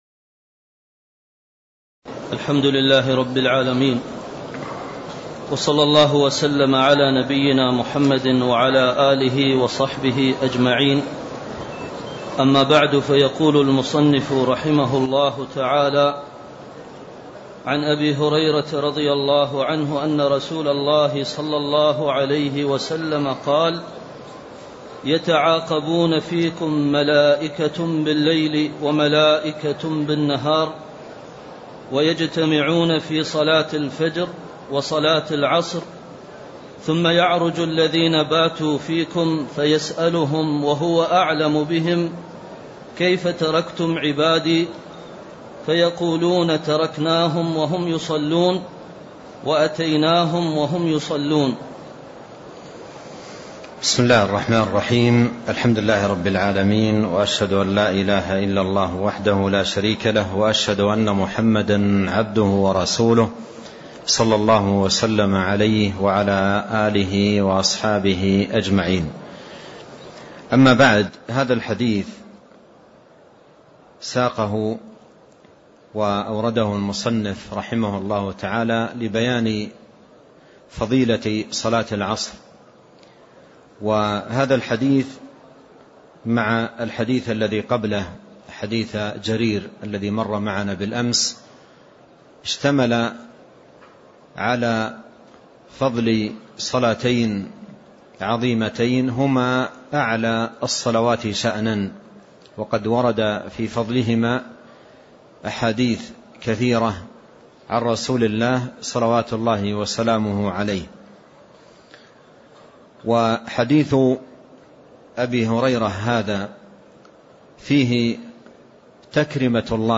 تاريخ النشر ١٦ ربيع الأول ١٤٣٤ هـ المكان: المسجد النبوي الشيخ